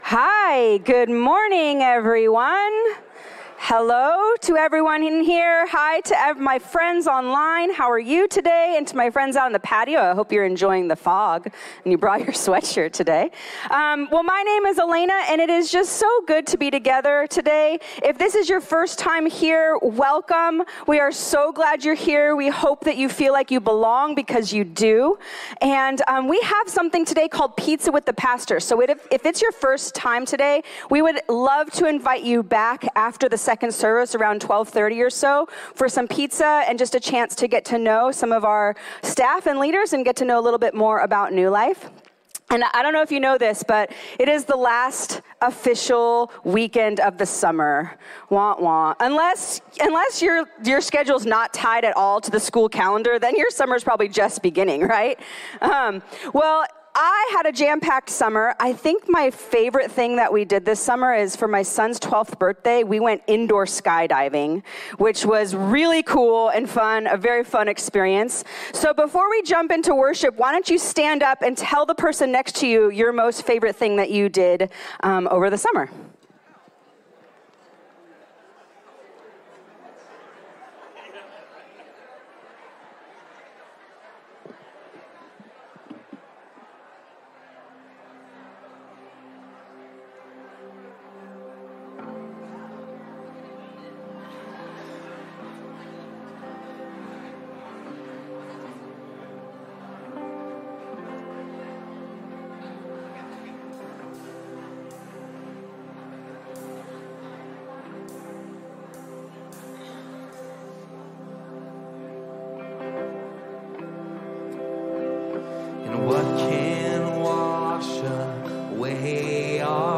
A message from the series "Great Things."